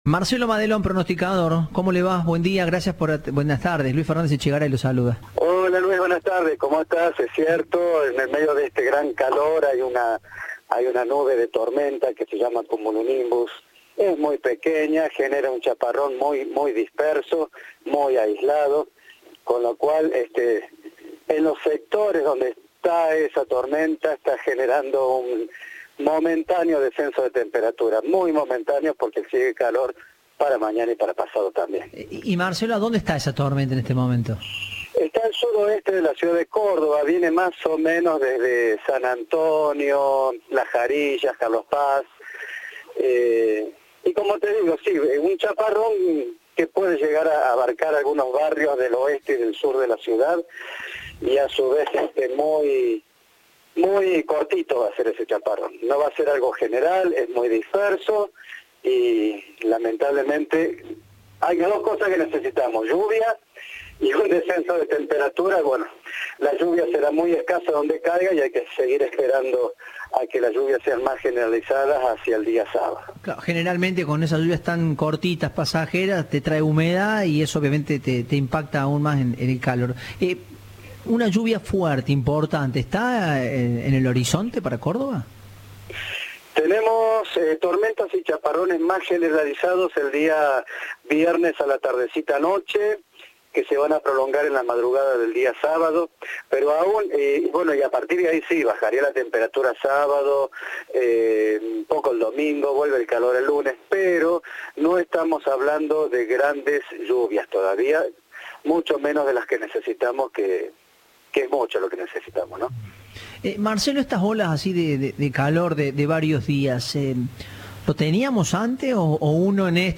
Entrevista de "Informados, al regreso".